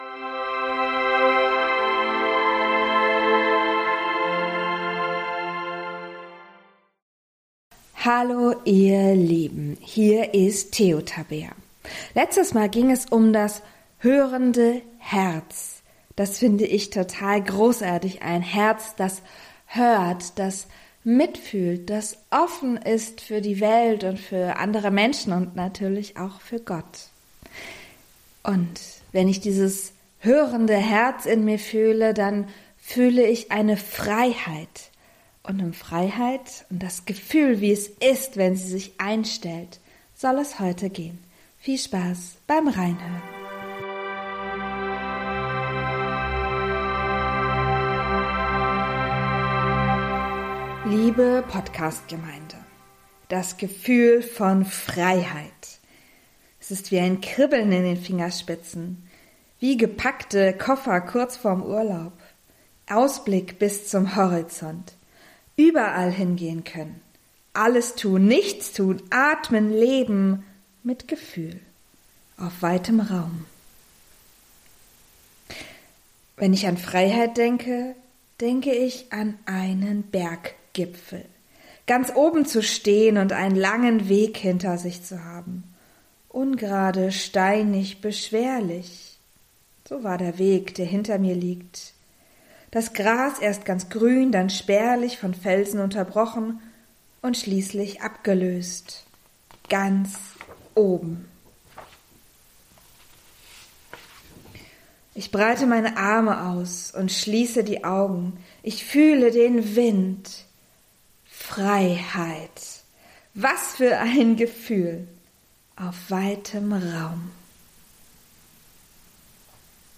Freiheit vorwärts ~ Predigten